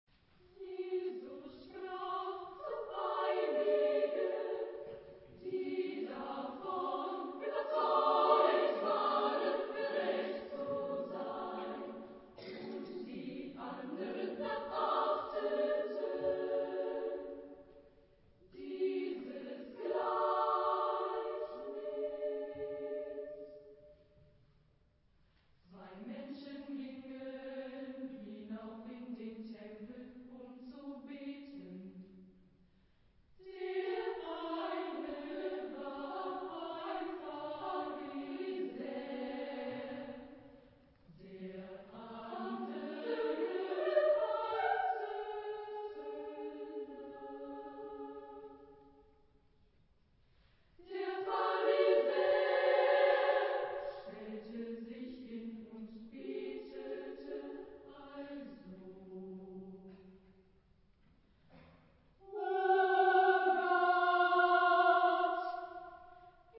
Genre-Stil-Form: geistlich ; Motette
Charakter des Stückes: erzählend
Chorgattung: SSAA  (4 Frauenchor Stimmen )
Tonart(en): fis-moll